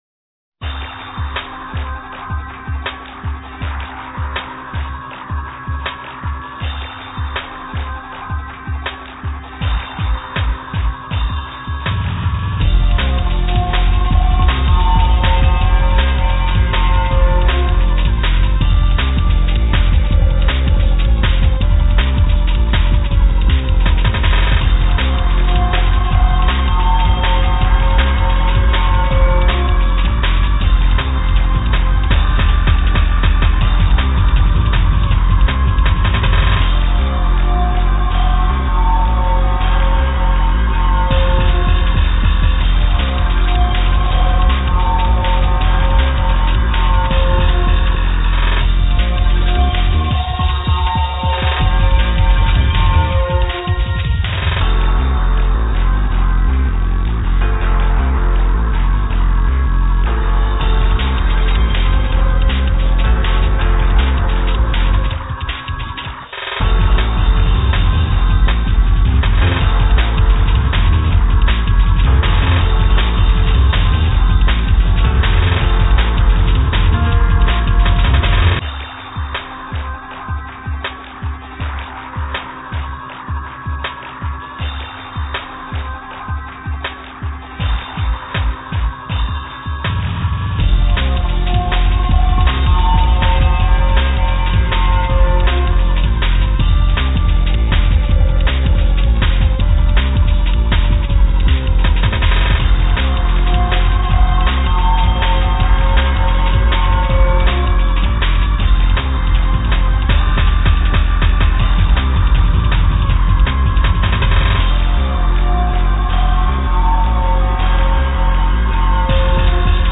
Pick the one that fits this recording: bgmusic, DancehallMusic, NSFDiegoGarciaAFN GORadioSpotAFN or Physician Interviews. bgmusic